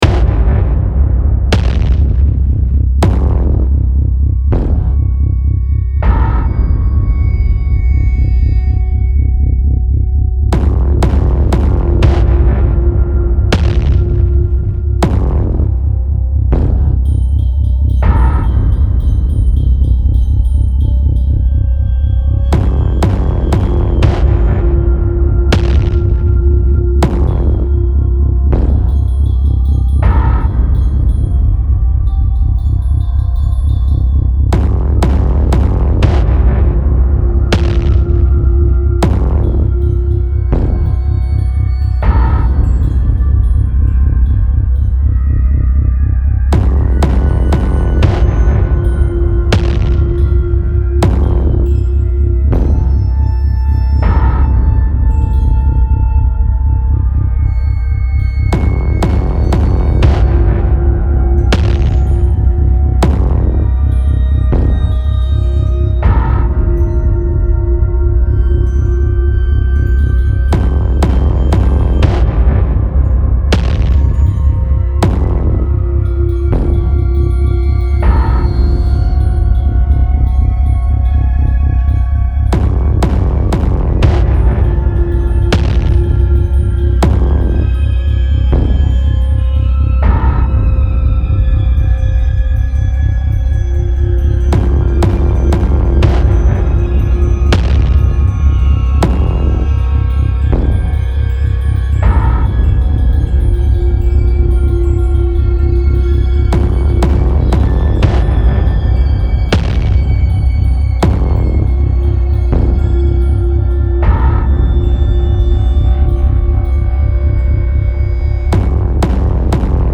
Style Style Ambient, Soundtrack
Mood Mood Dark, Intense, Scary +1 more
Featured Featured Drums, Synth
BPM BPM 60